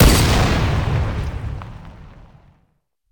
plasrifle.ogg